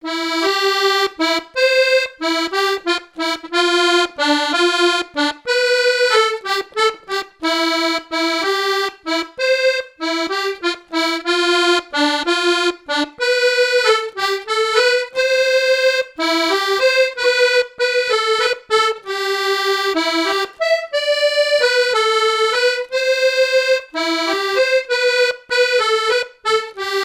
danse : valse
Répertoire à l'accordéon diatonique
Pièce musicale inédite